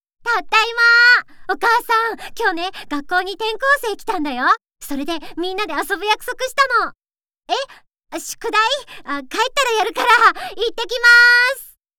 優しく、誠実な声が特徴です。
元気な女の子
female05_45.wav